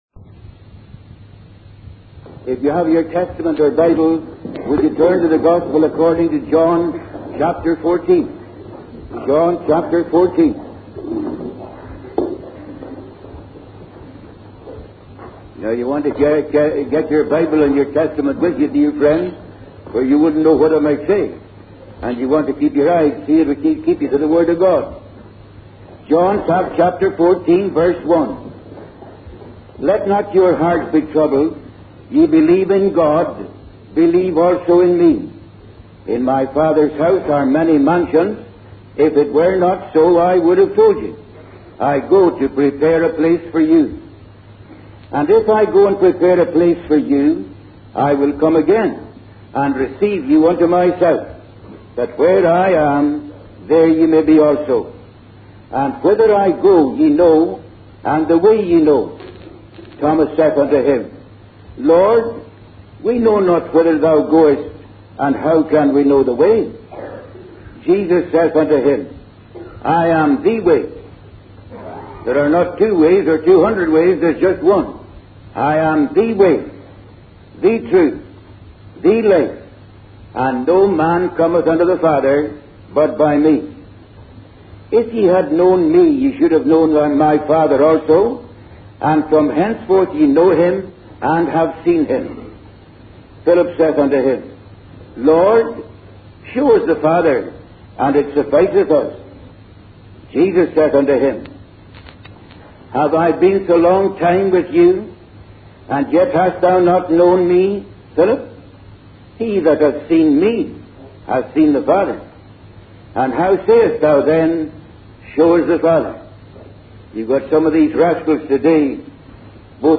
In this sermon, the speaker shares his personal experience of being converted to Christianity in a moment's time. He emphasizes the importance of being born again and being part of God's family.